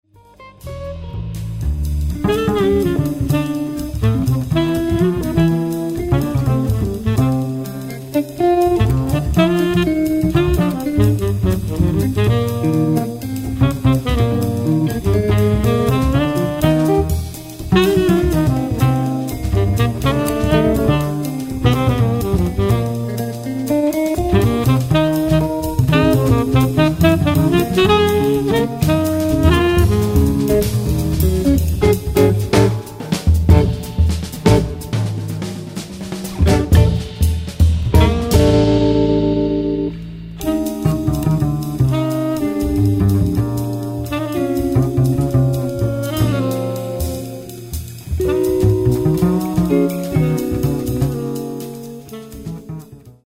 saxofon